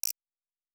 pgs/Assets/Audio/Sci-Fi Sounds/Interface/Digital Click 06.wav at master
Digital Click 06.wav